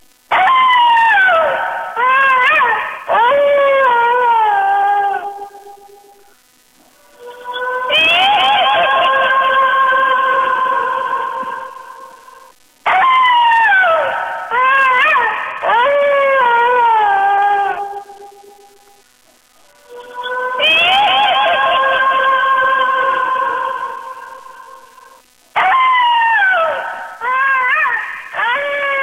Horror Tone Efecto de Sonido Descargar
Horror Tone Botón de Sonido